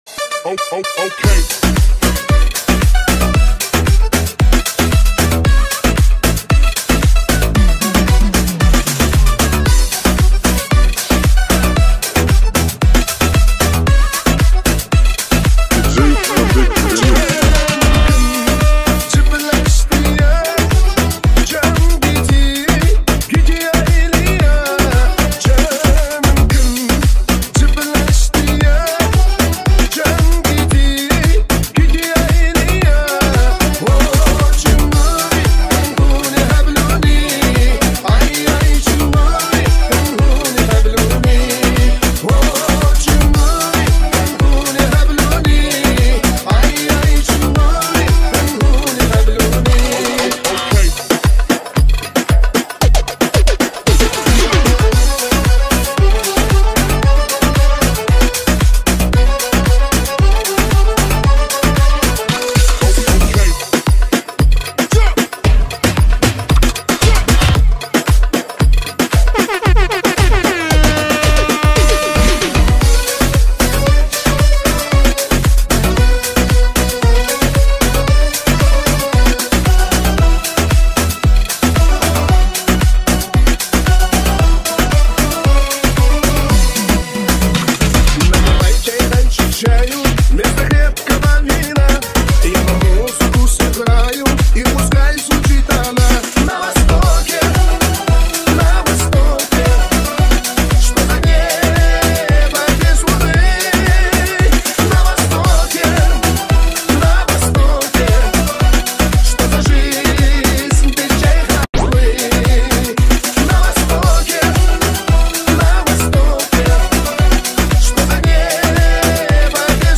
Категория: Новые Ремиксы